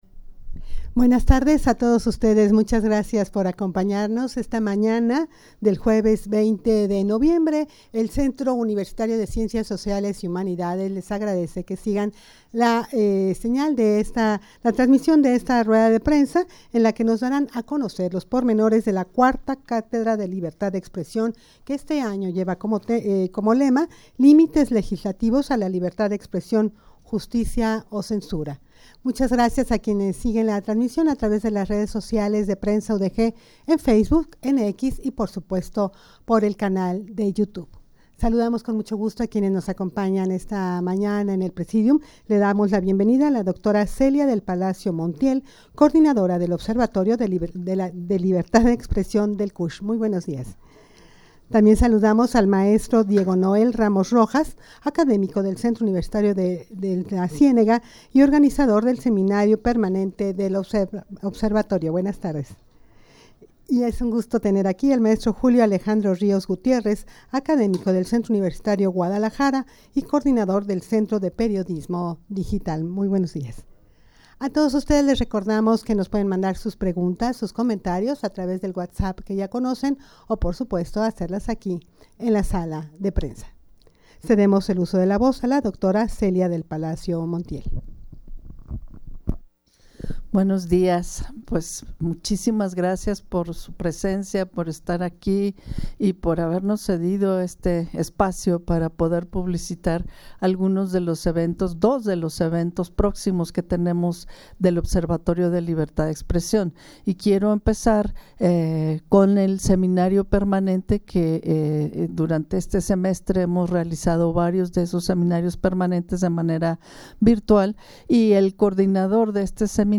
Audio de la Rueda de Prensa
rueda-de-prensa-para-dar-a-conocer-los-pormenores-de-la-iv-catedra-de-libertad-de-expresion.mp3